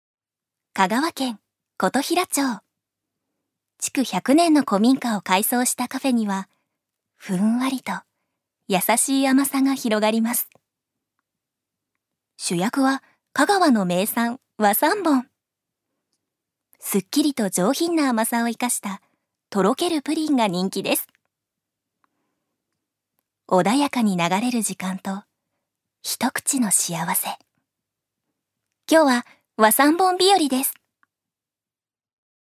預かり：女性
ナレーション２